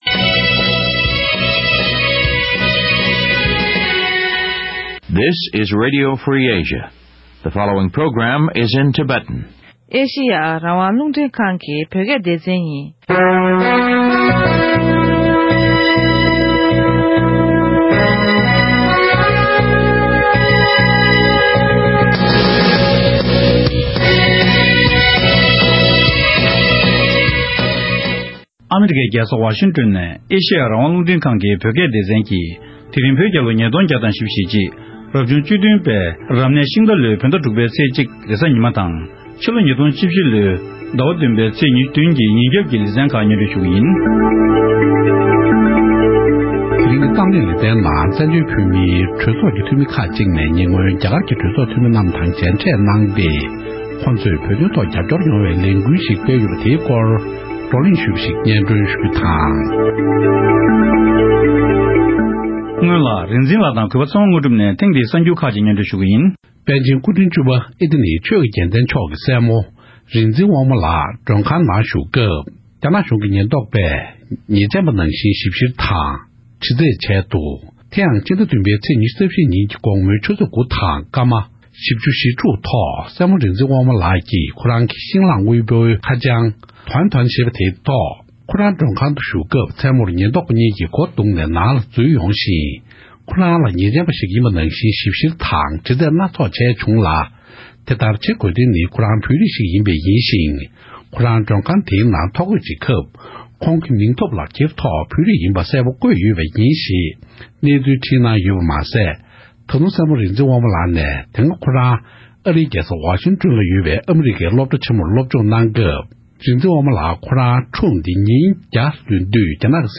༄༅། །ཐེངས་འདིའི་གཏམ་གླེང་གི་ལེ་ཚན་ནང་།